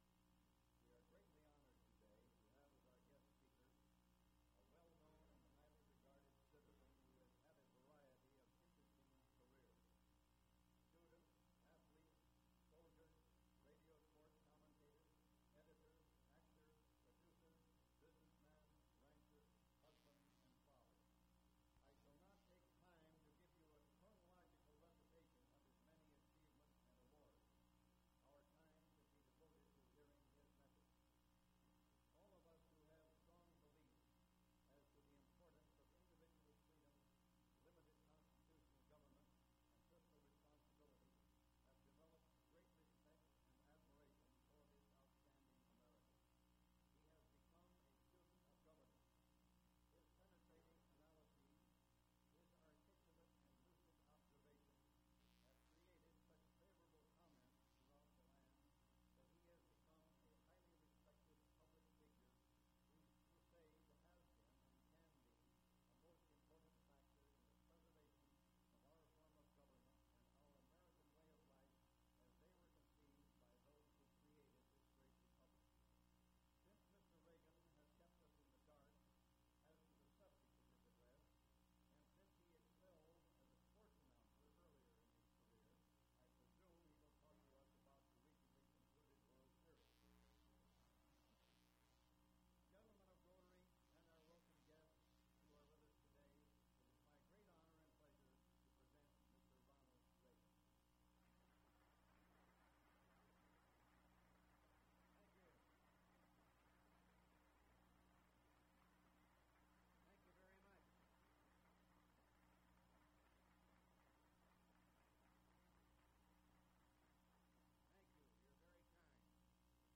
“National Policy” Speech by Ronald Reagan at the Rotary Club, Los Angeles